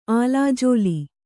♪ ālājōli